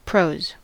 Ääntäminen
Ääntäminen US Tuntematon aksentti: IPA : /prˈəʊz/ Haettu sana löytyi näillä lähdekielillä: englanti Käännös Substantiivit 1.